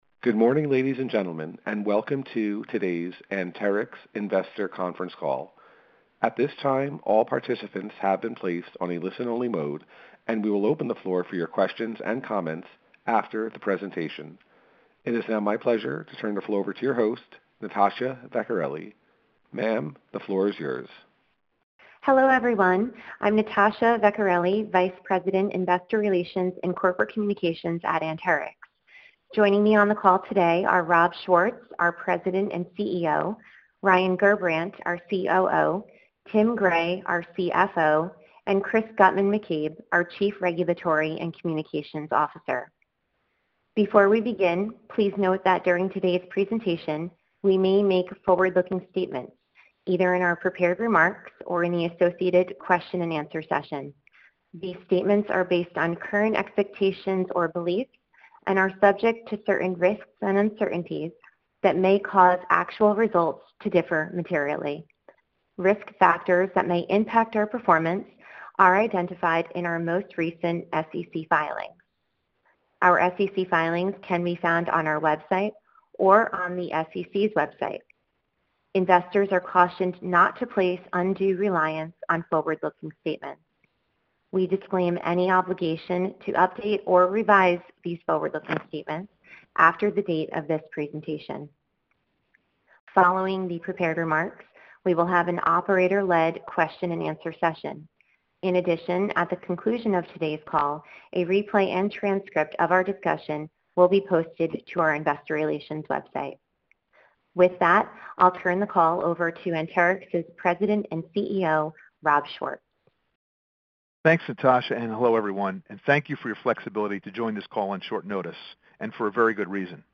October 2022 Anterix Investor Conference Call | Anterix